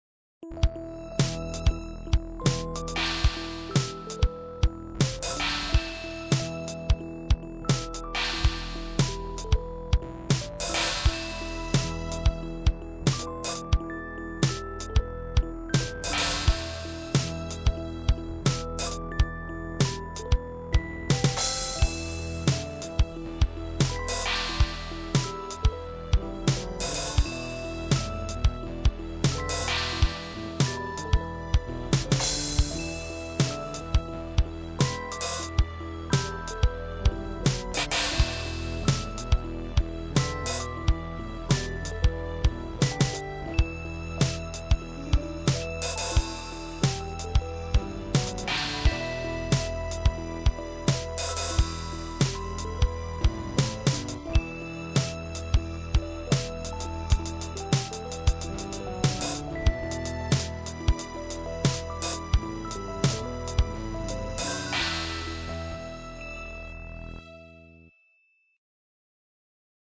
Electric Piano with Rythym Track